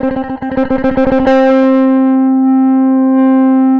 Track 13 - Clean Guitar.wav